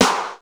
Snaredrum-08.wav